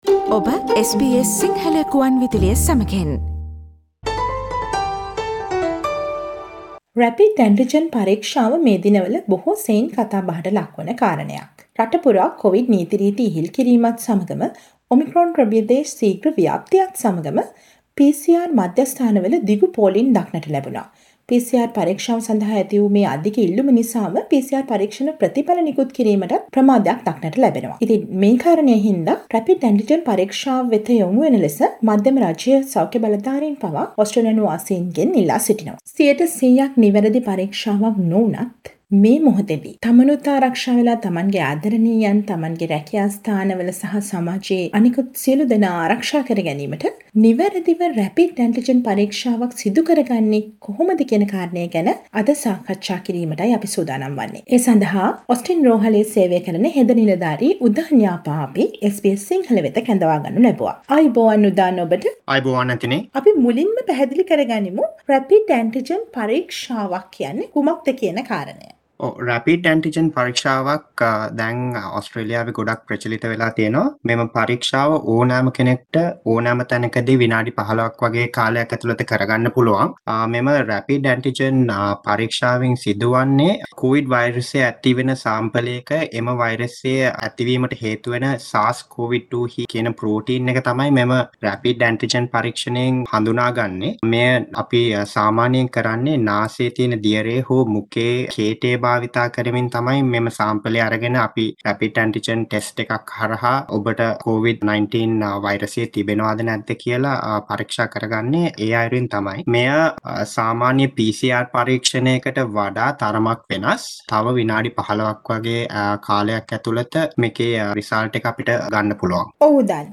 SBS සිංහල රේඩියෝව සිදුකළ සාකච්ඡාවට සවන් දෙන්න.